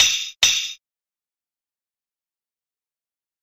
フリー効果音：カシャン
カシャンとしたときの音を再現してみました！金属が当たる音っぽい効果音なので鍛冶や錬成シーンにぴったり！
clank.mp3